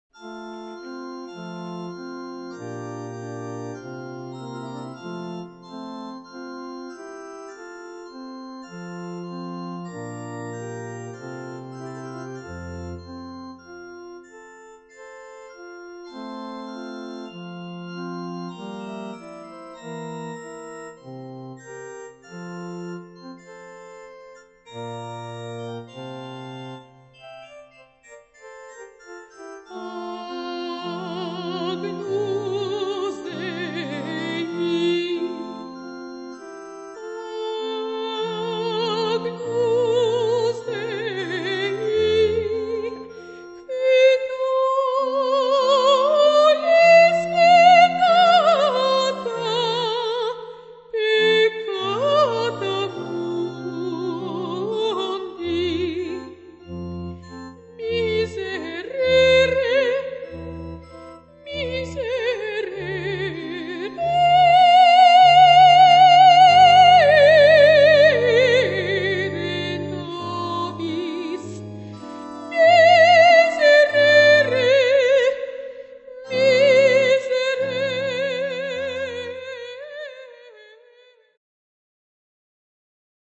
Aria
C-dur